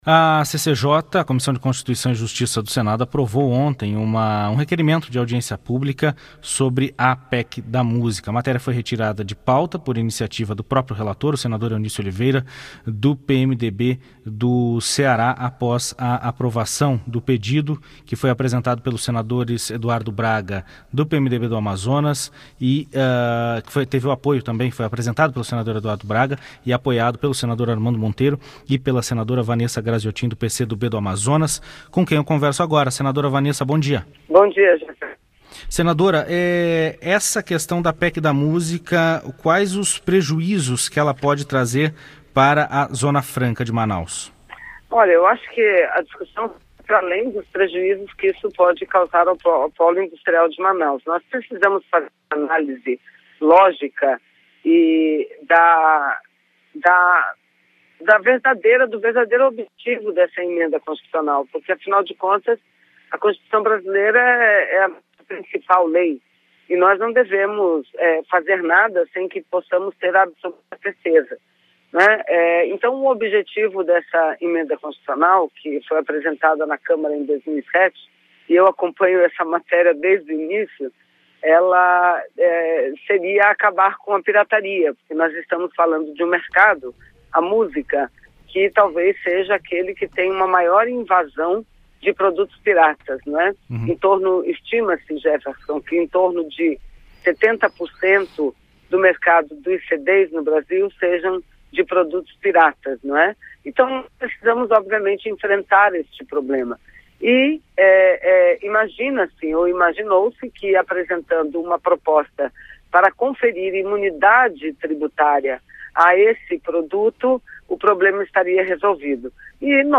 Entrevista com a senadora Vanessa Grazziotin (PCdoB-AM).